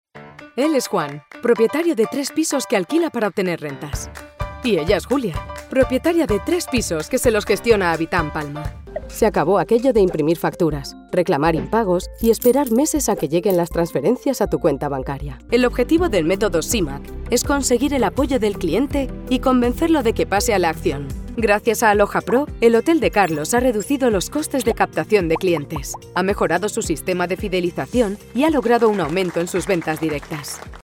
Spanish - Female
Explainer Showreel
Warm, Trusting, Friendly, Informative